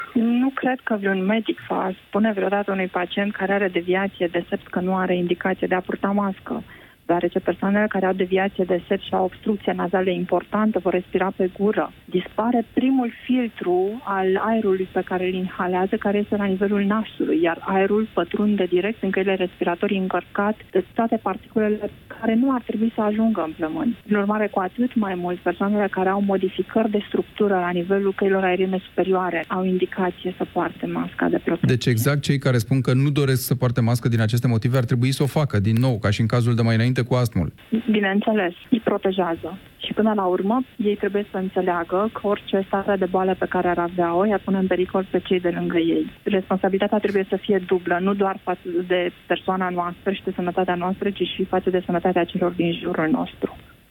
în emisiunea Piața Victoriei, la Europa FM